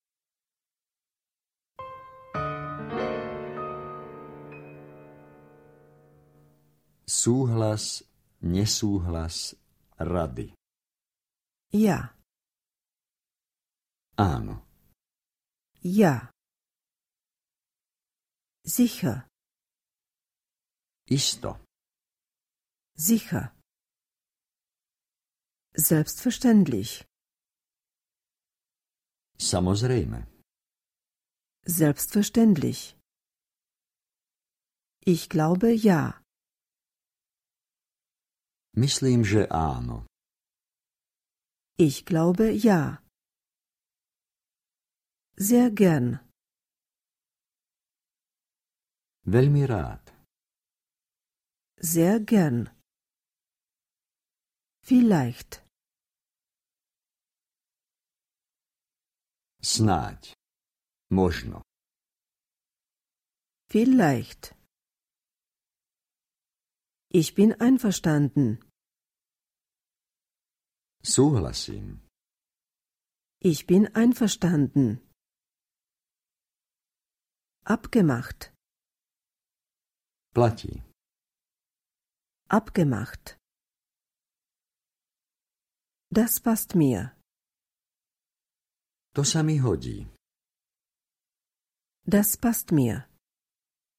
Audiokniha
Čte: Různí interpreti